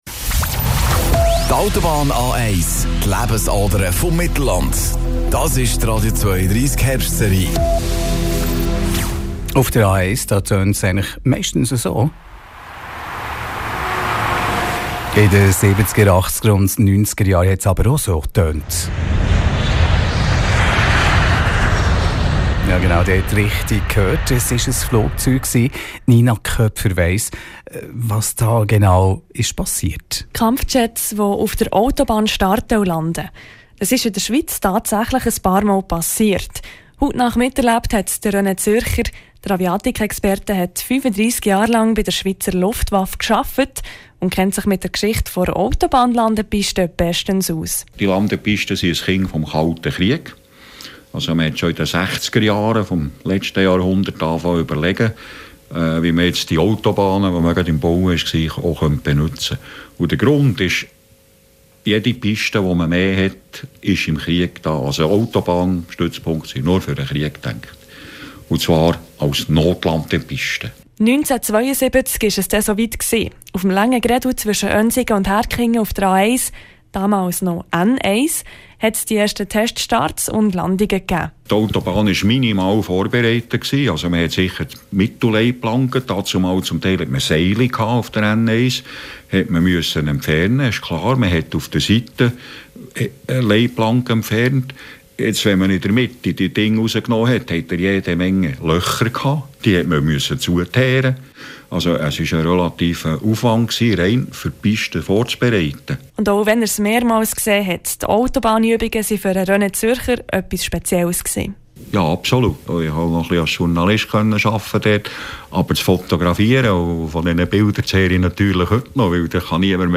Ein Aviatikexperte erklärte mir die vielfältigen Einsatzmöglichkeiten der A1: